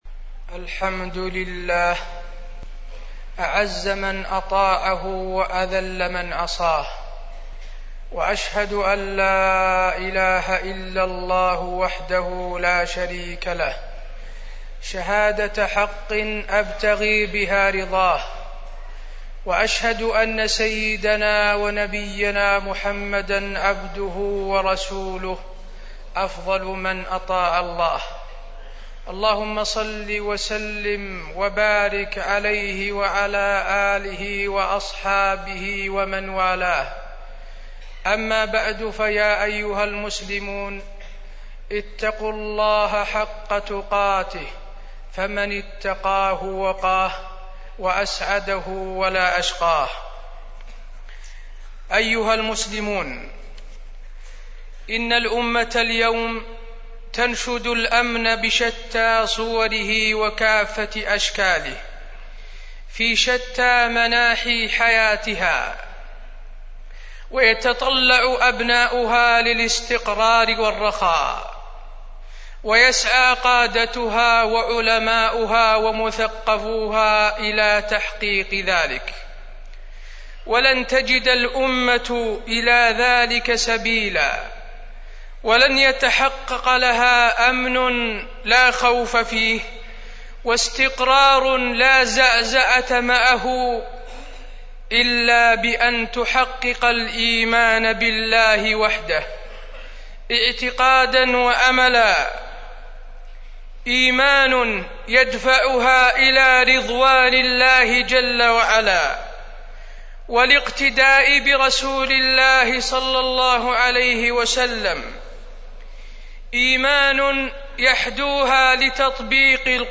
تاريخ النشر ٢١ ذو القعدة ١٤٢٦ هـ المكان: المسجد النبوي الشيخ: فضيلة الشيخ د. حسين بن عبدالعزيز آل الشيخ فضيلة الشيخ د. حسين بن عبدالعزيز آل الشيخ الإيمان بالله